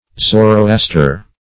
Zoroaster \Zo`ro*as"ter\, prop. n.